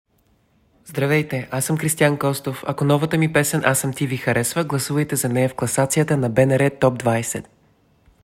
Повече за песента “Аз съм ти” чуйте от Кристиан Костов в интервю за слушателите на БНР Топ 20: